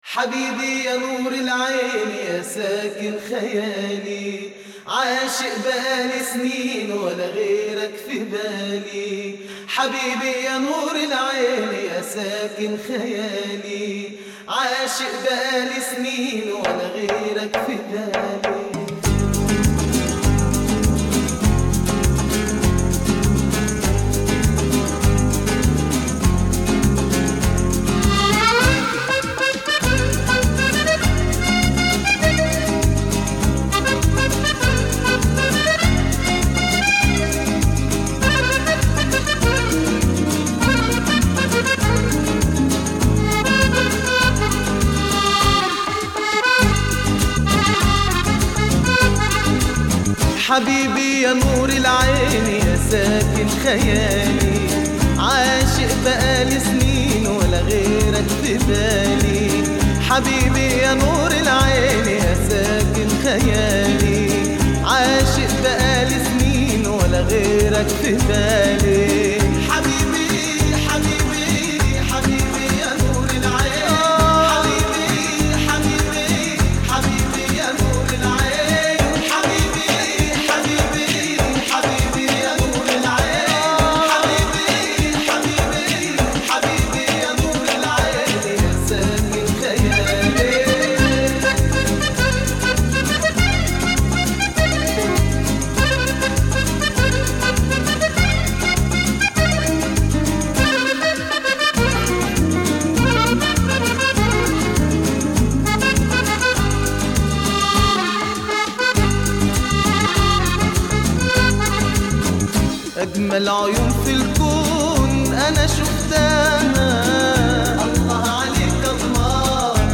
vostochnaya_muzyka___habibi_zaycev_.mp3